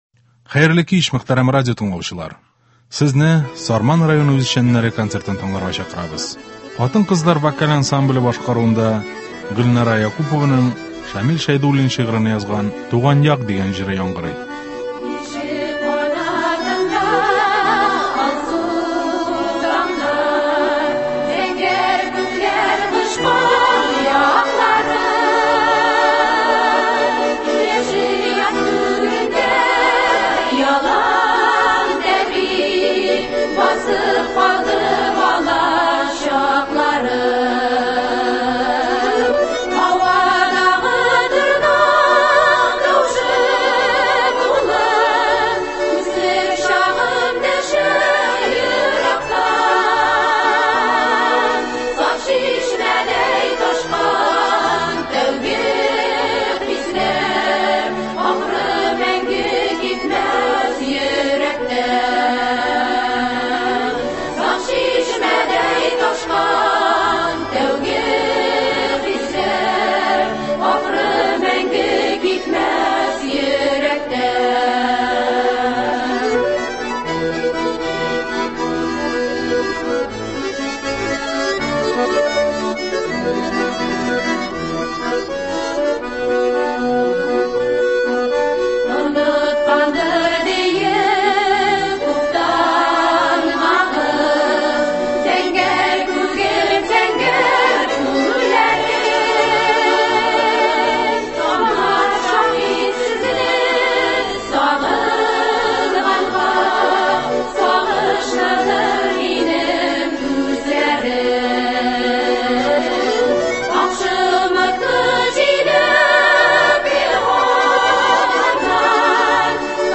Концерт (31.10.22)